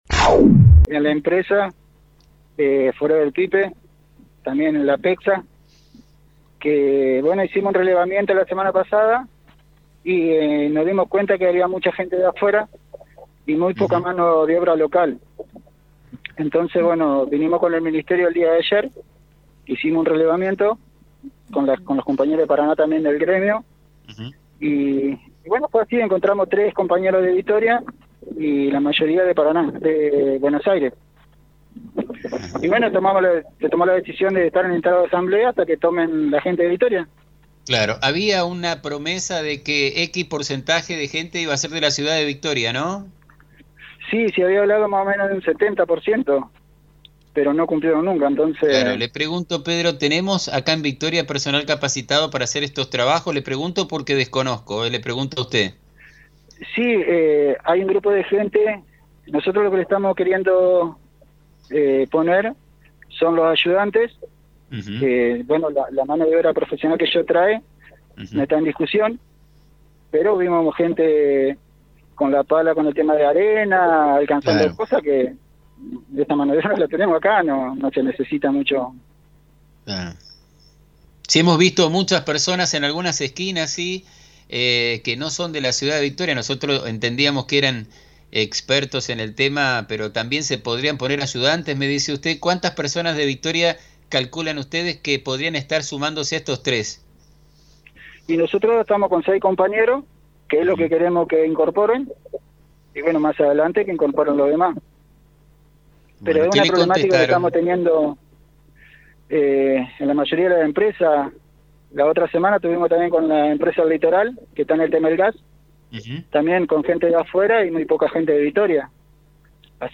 Movil-con-Empl-de-Cloacas-web.mp3